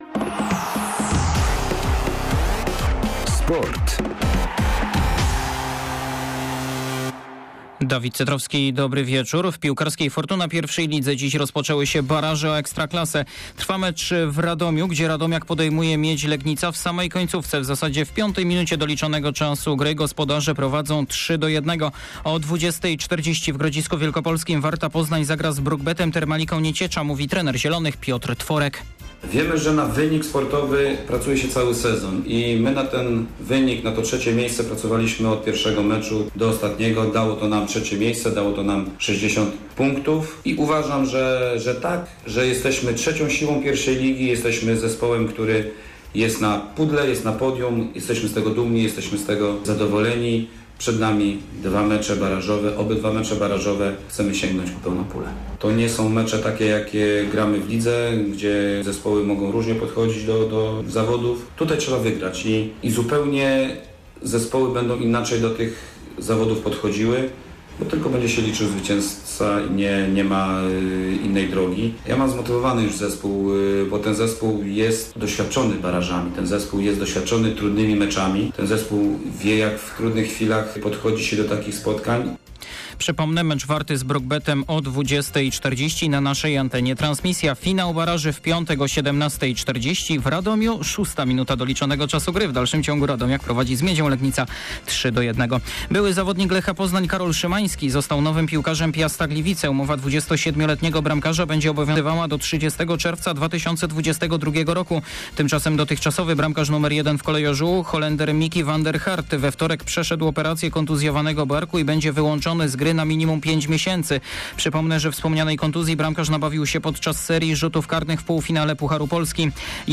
28.07. SERWIS SPORTOWY GODZ. 19:05